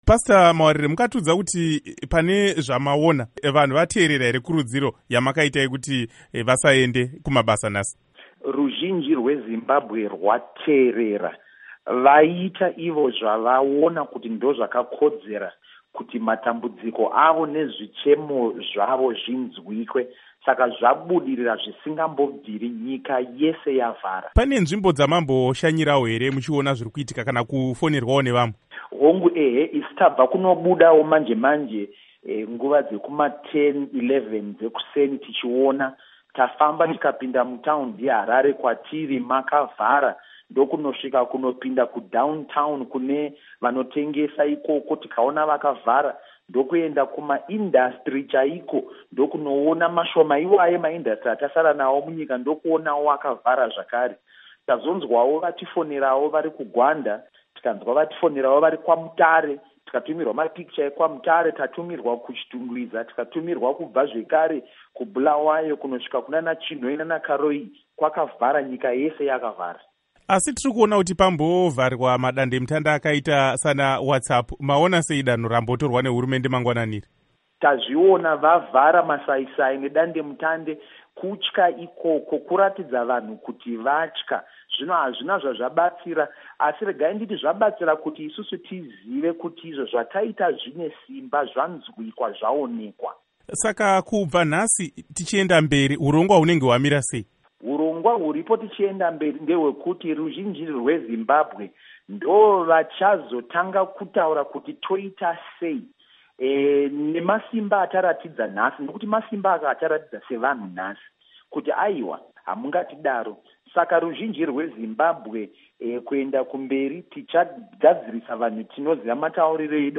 Hurukuro naPastor Evan Mawarire